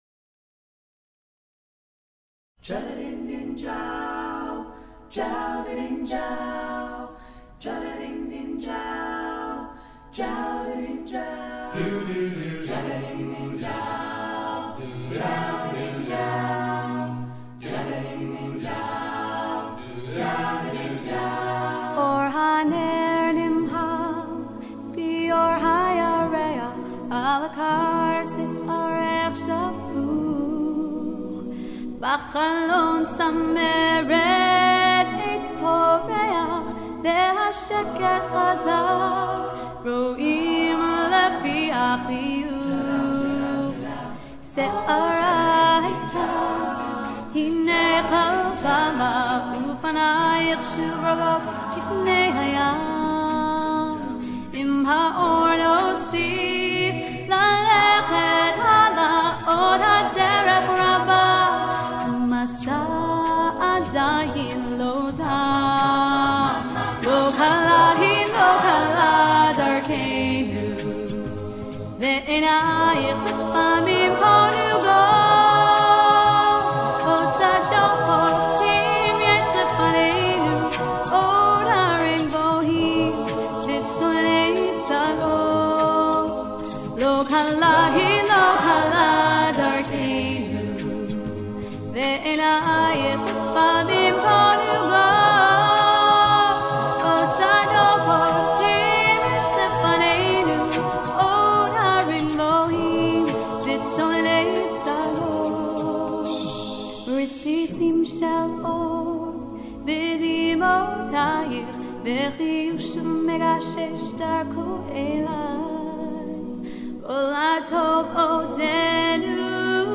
Genre: Israeli
Contains solos: Yes